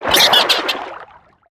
Sfx_creature_arrowray_death_01.ogg